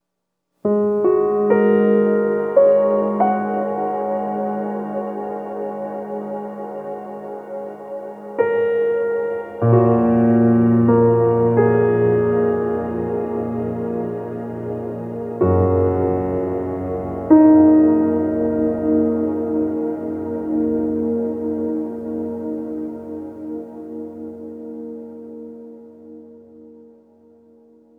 Reverb Piano 10.wav